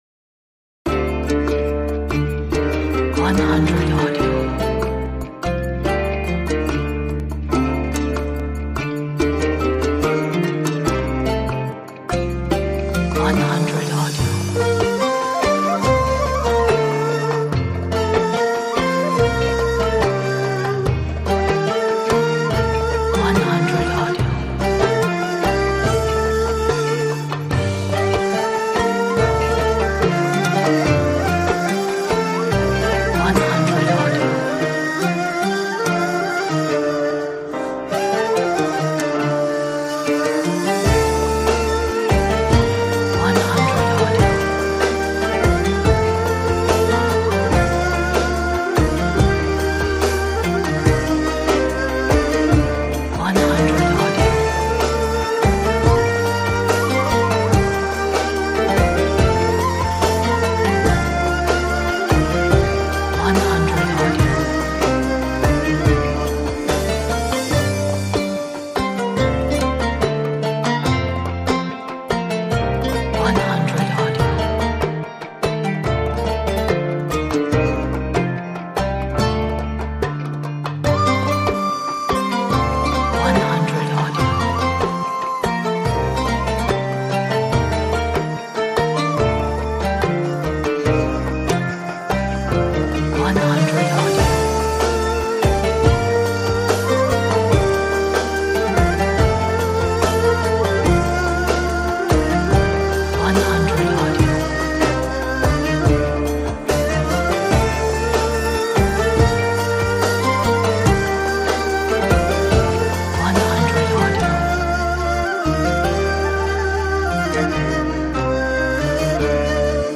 Nay, Oud and Qanun on powerful Turkish drums!